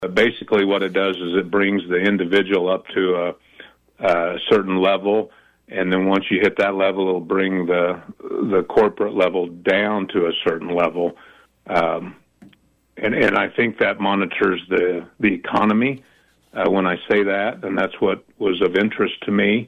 Schreiber and Argabright offered their comments during separate interviews on KVOE’s Morning Show Wednesday where another major talking point focused on the passage of Senate Bill 269, known as the “income tax trigger bill,” which aims to reduce individual and corporate income tax rates to as low as 4 percent.